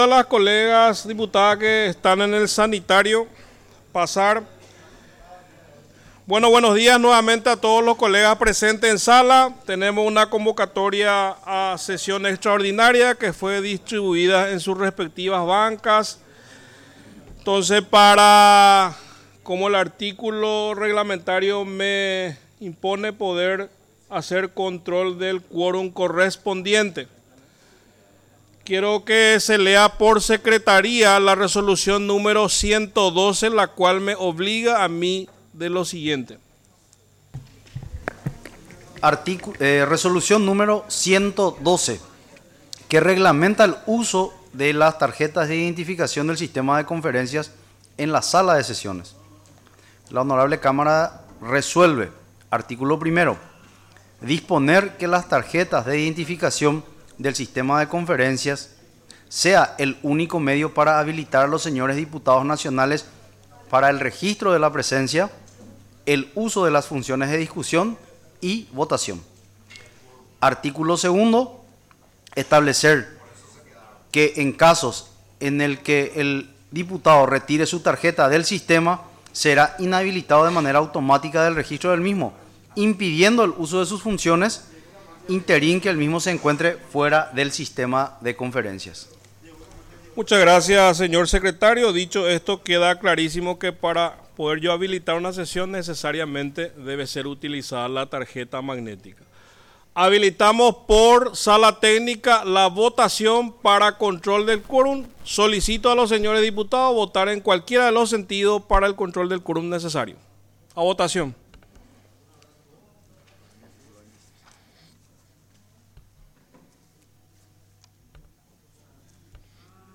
Sesión Extraordinaria, 7 de octubre de 2025